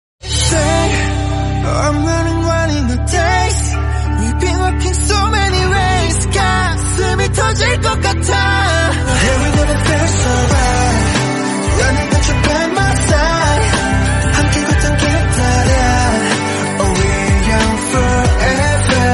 his voice is so beautiful